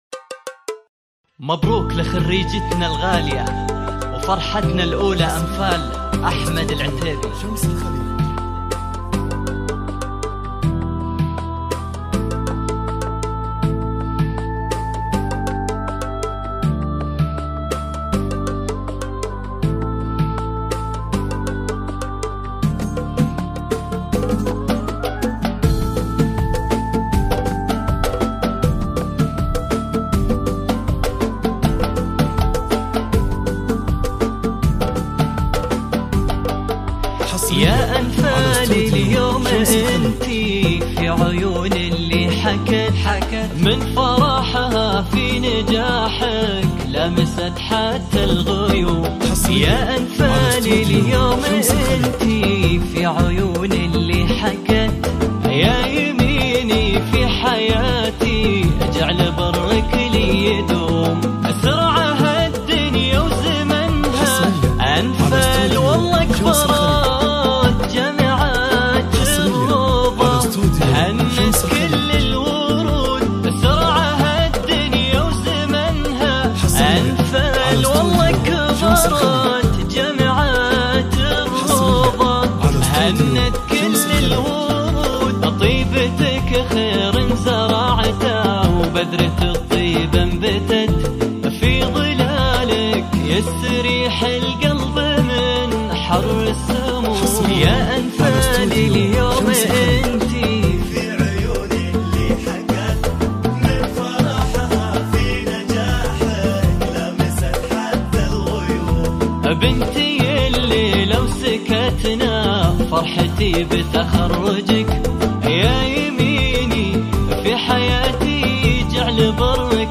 زفات تخرج
زفات بدون موسيقى